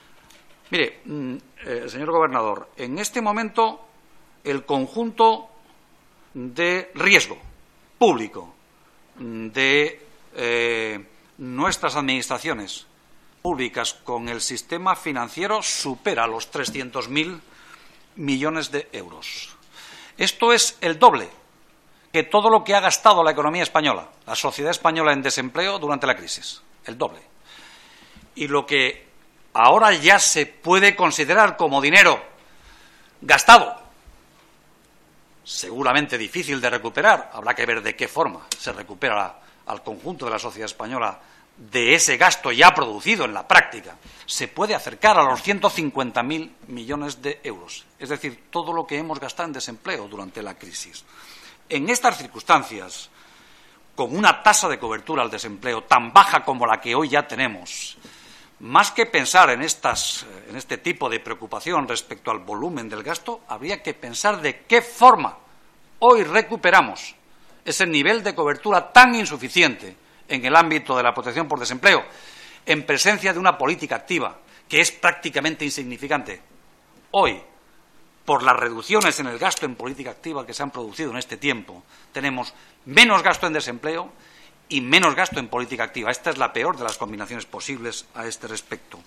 Comisión de Economía.